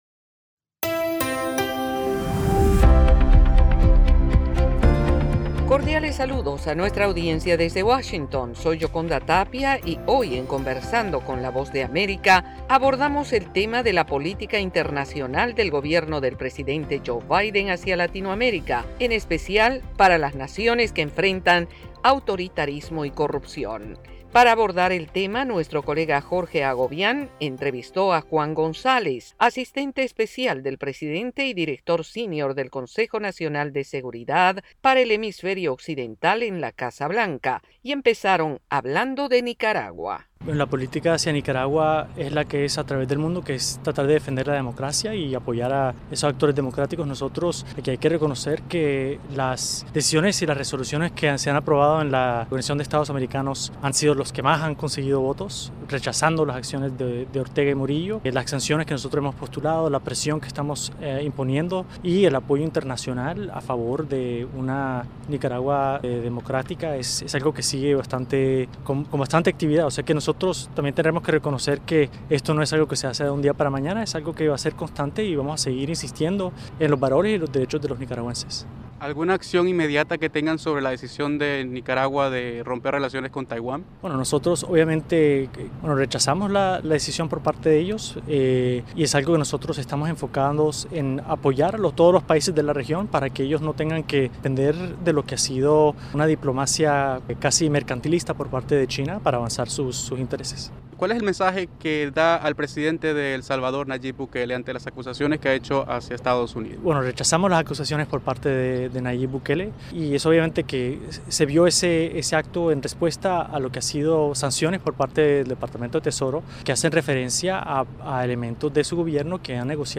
Conversamos con Juan González, alto funcionario de la Casa Blanca para el Hemisferio Occidental hablando sobre la política exterior de la Administración Biden.